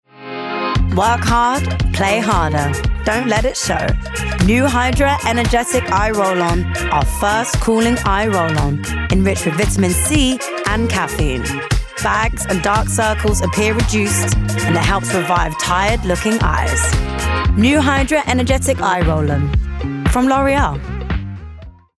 London
Cool Husky Friendly